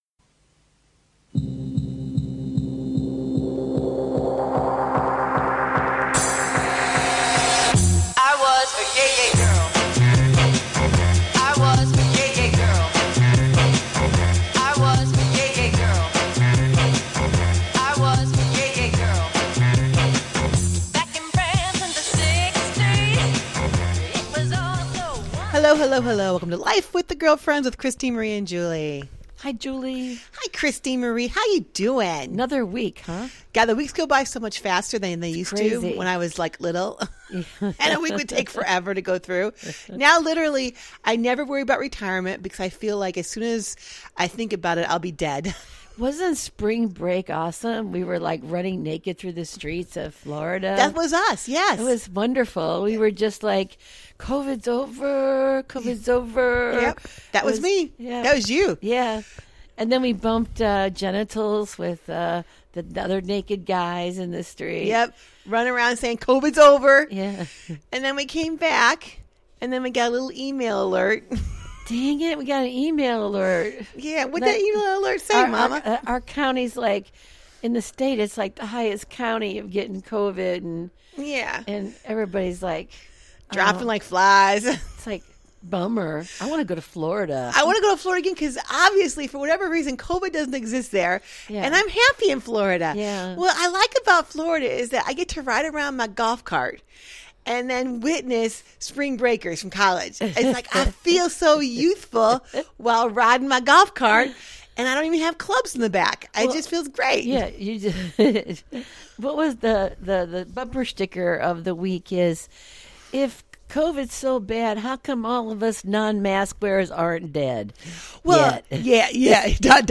They welcome a wide range of guest to their den for some juicy conversation.
And join the girlfriends up close and personal for some daily chat that’s humorous, wholesome, and heartfelt.